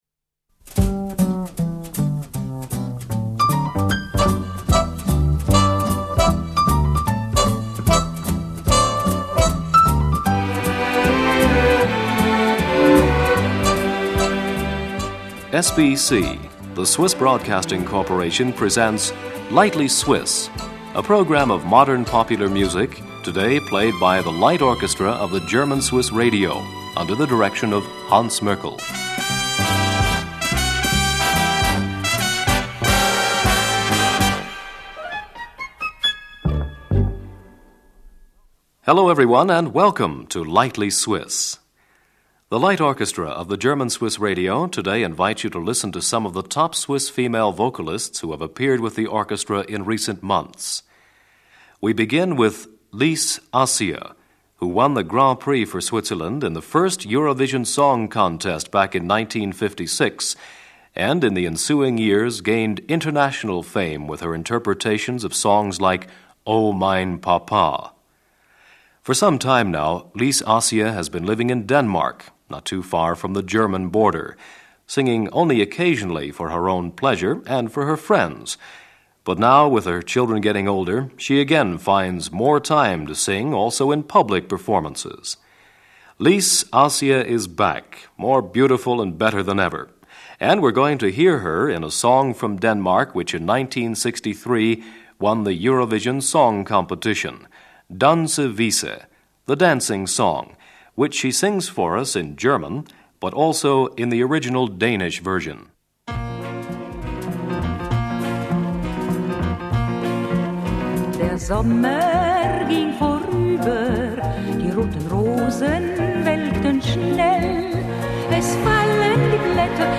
vocal.
guitar.